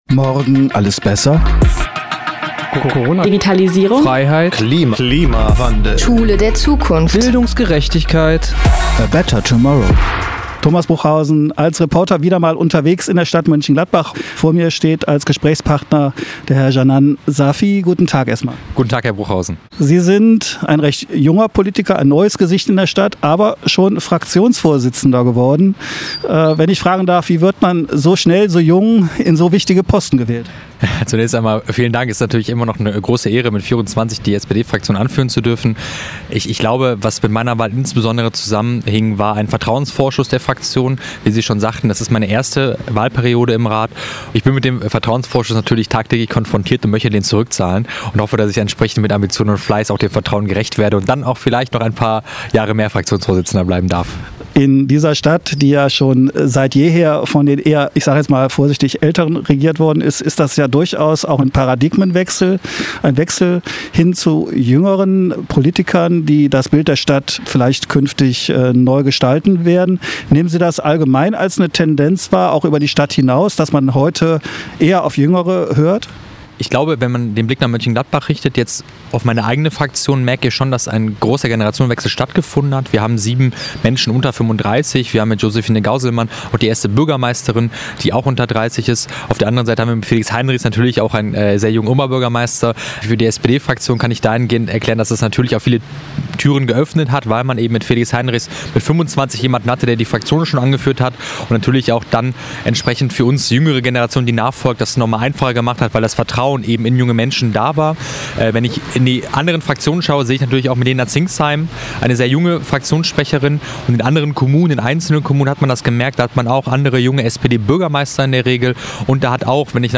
Janann Safi – der neue Fraktionsvorsitzende der SPD-Ratsfraktion im Gespräch
Interview-Safi-SPD-Komplett-TB_WEB.mp3